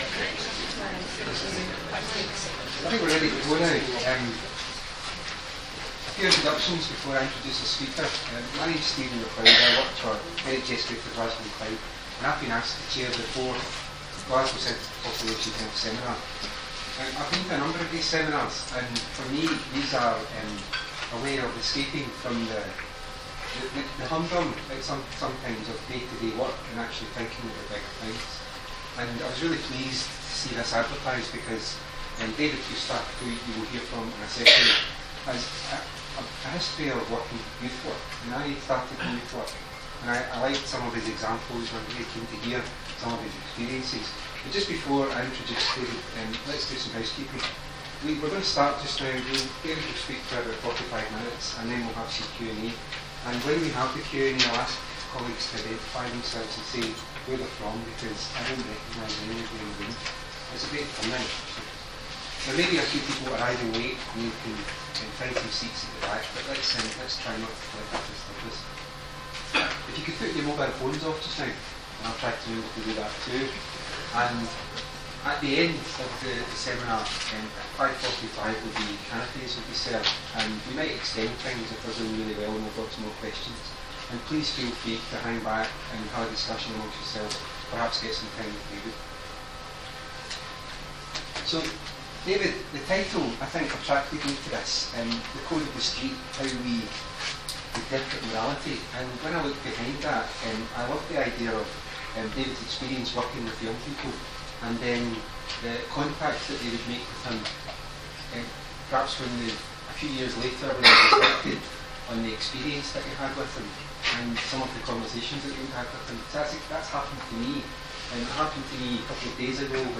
Seminar
Radisson Blu, Glasgow, United Kingdom